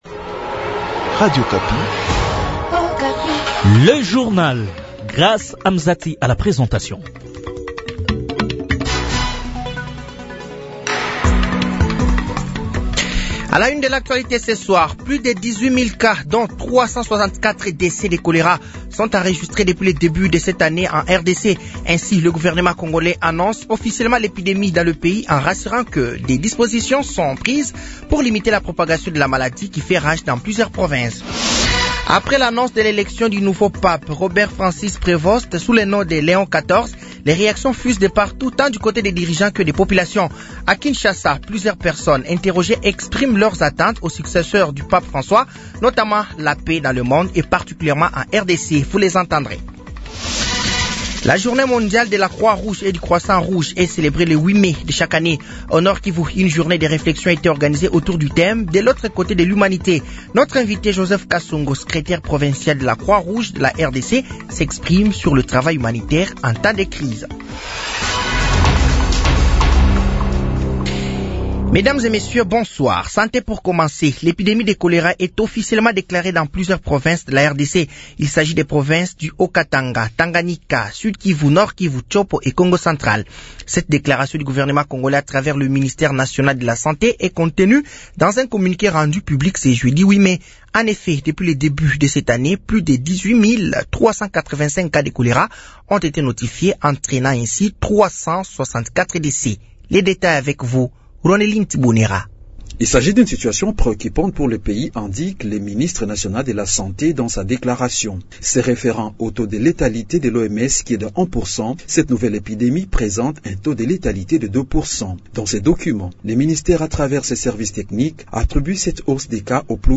Journal français de 18h de ce vendredi 09 mai 2025